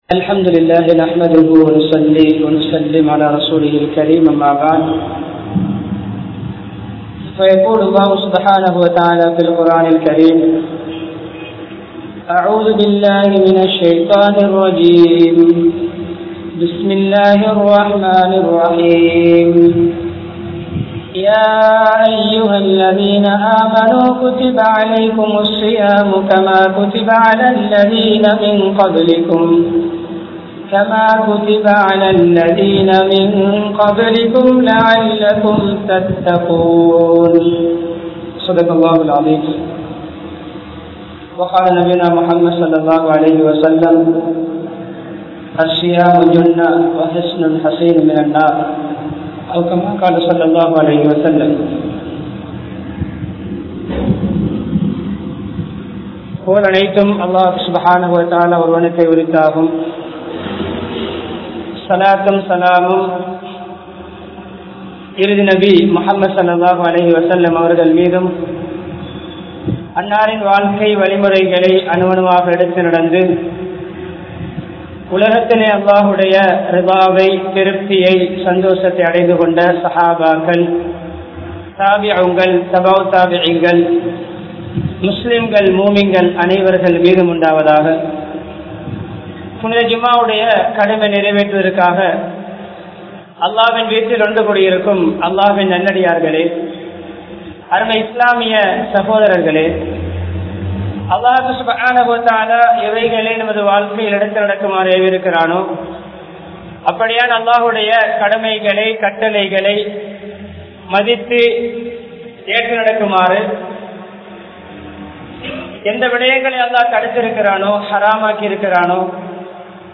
Nabi(SAW)Avarhalin Ramalan Kaalam (நபி(ஸல்)அவர்களின் ரமழான் காலம்) | Audio Bayans | All Ceylon Muslim Youth Community | Addalaichenai
Kandauda Jumua Masjidh